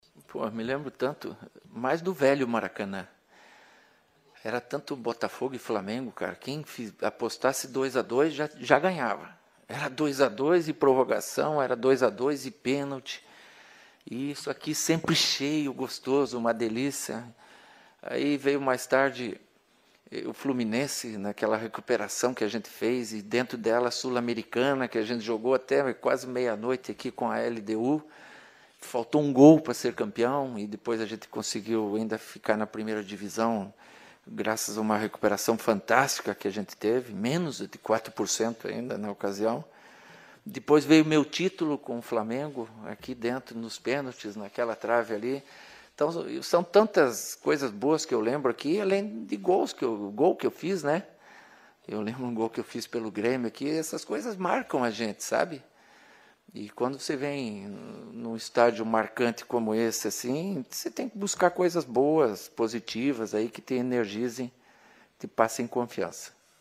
Na entrevista coletiva desta sexta-feira (29/1), Cuca não escondeu suas boas lembranças do velho Maracanã.